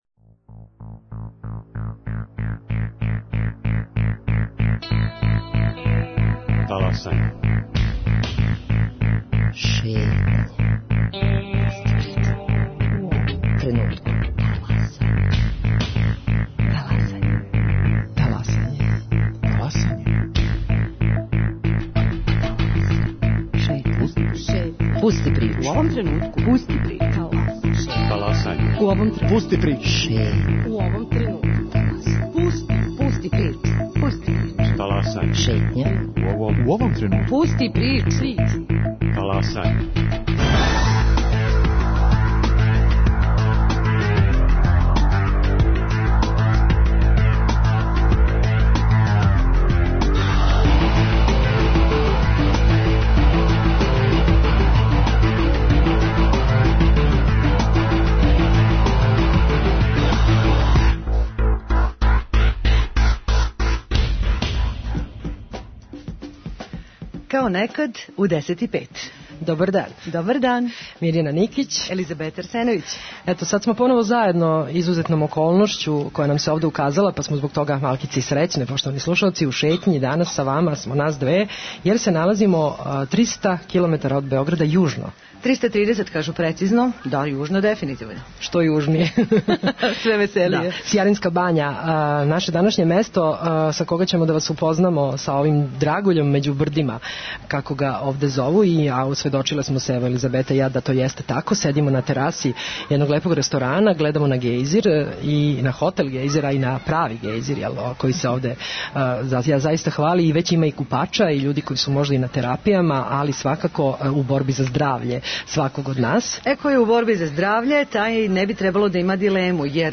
Шетњу емитујемо из Сијаринске бање, где је у току традиционална, јубиларна, 20. манифестација 'Гејзерске ноћи'.